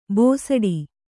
♪ bōsaḍi